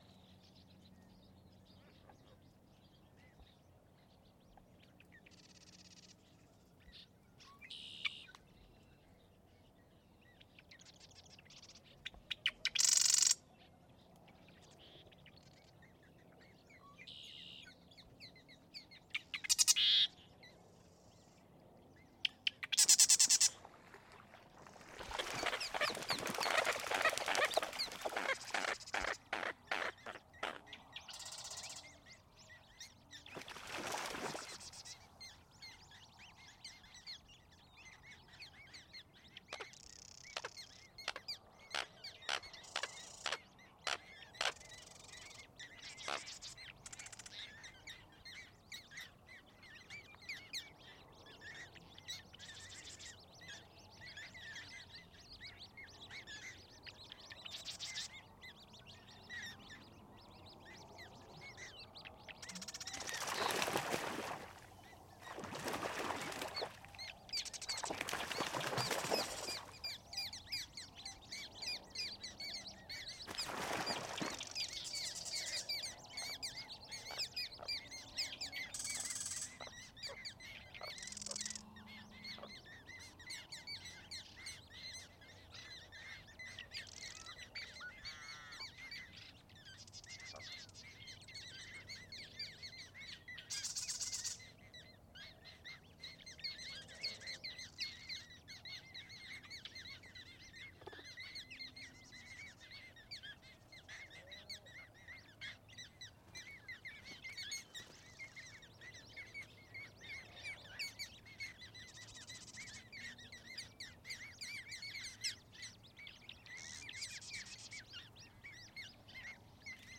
Altercation among American Coots with young